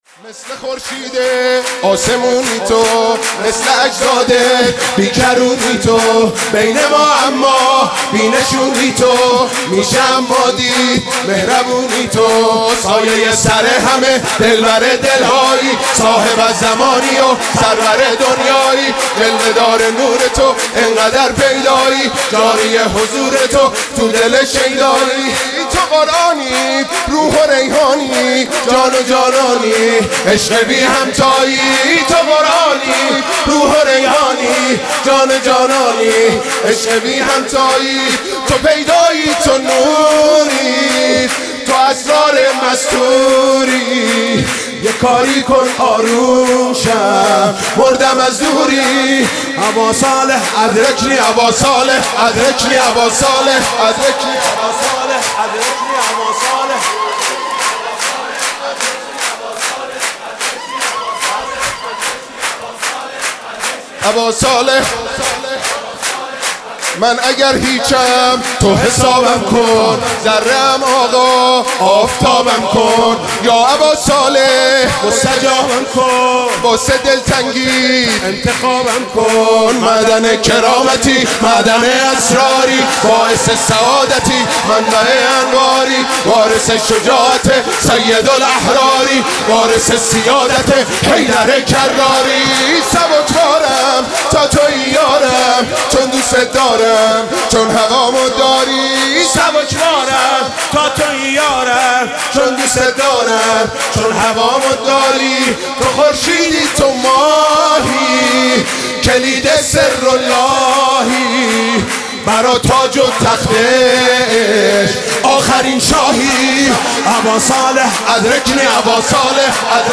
سرود: مثل خورشید آسمونی تو